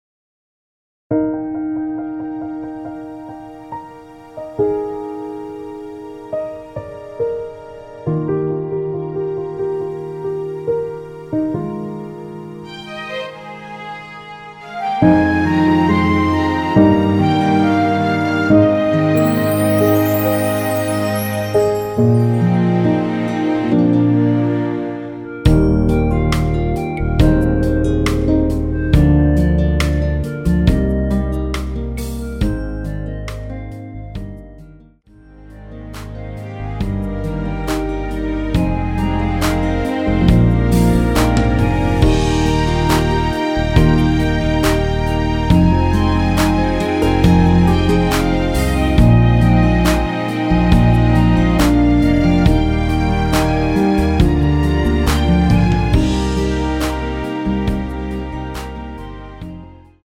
원키에서(-1)내린 멜로디 포함된 MR입니다.
Eb
앞부분30초, 뒷부분30초씩 편집해서 올려 드리고 있습니다.
중간에 음이 끈어지고 다시 나오는 이유는